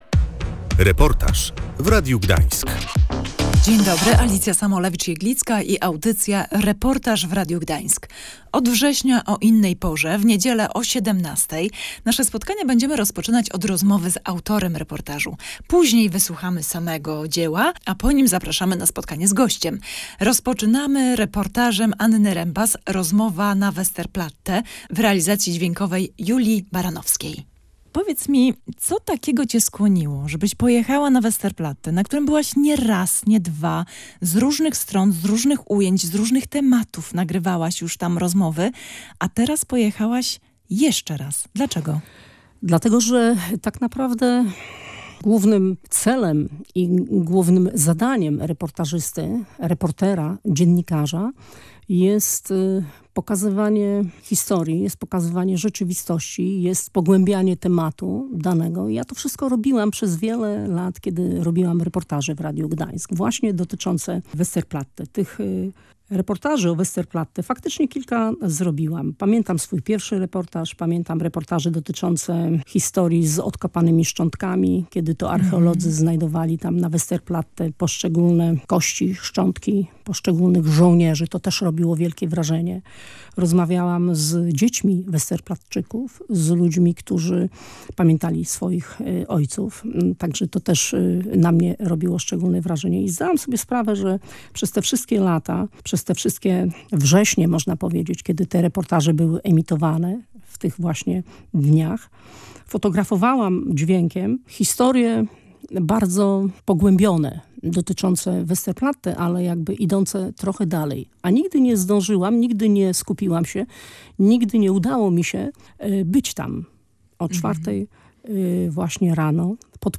Po raz kolejny uczczono bohaterskich obrońców. Posłuchaj reportażu „Rozmowa na Westerplatte”.
Pierwszego września o godzinie 4:45 na Westerplatte zawyły syreny alarmowe.
Na Westerplatte, mimo wczesnej pory, przyjechały także tłumy gdańszczan.